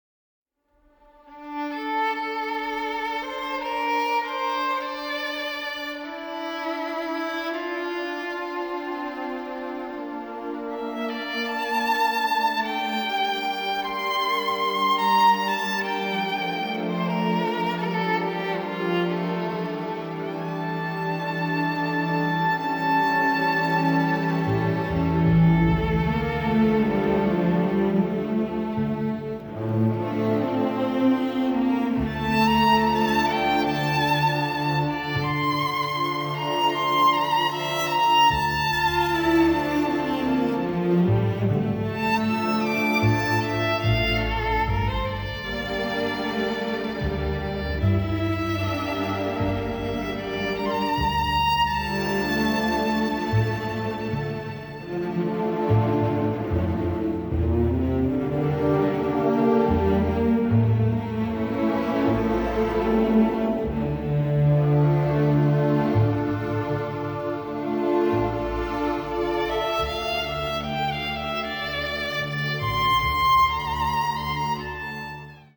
a rousing score